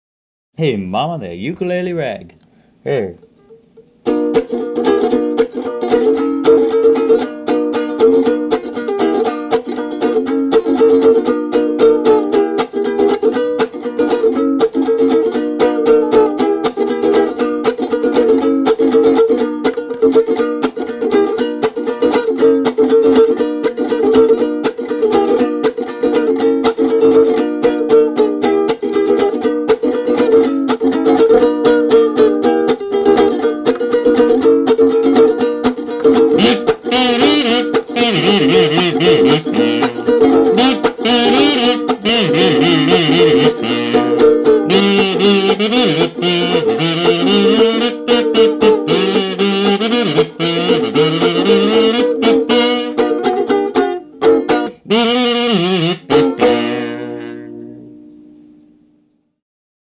R & B